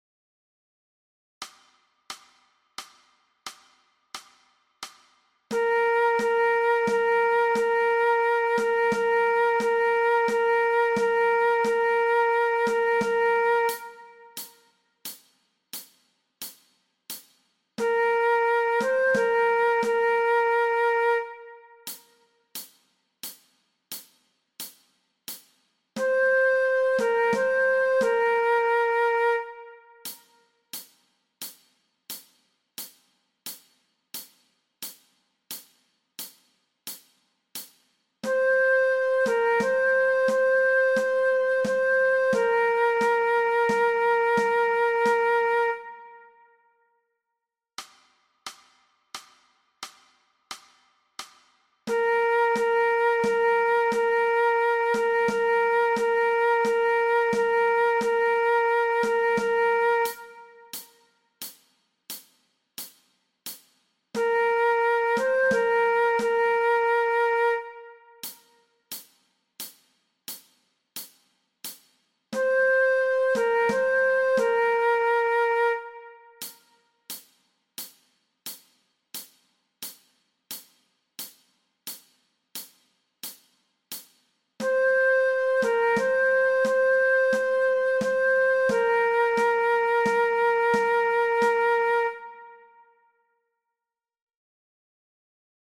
Aangepaste oefening NT 3.0 Dwarsfluit
Dwarsfluit aangepast
MEESPEELTRACK-NT-3.0-Fluit-alternate.mp3